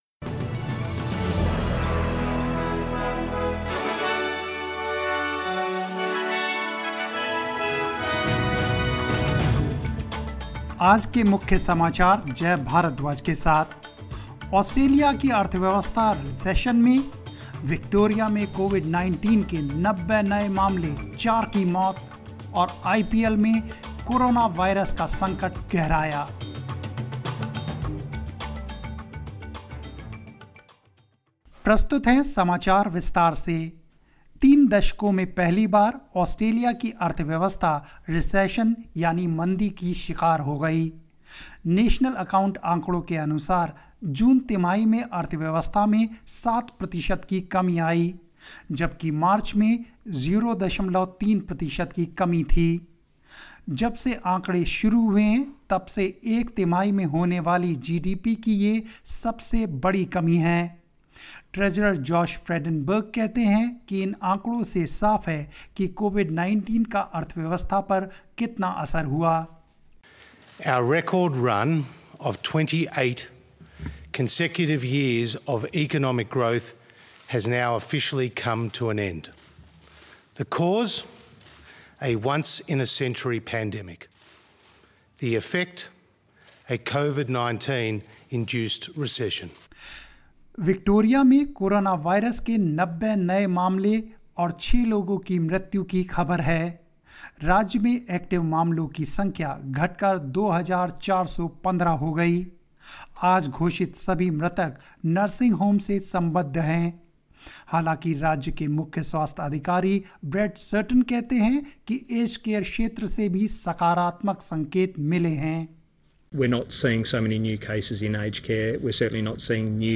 News in Hindi 02 September 2020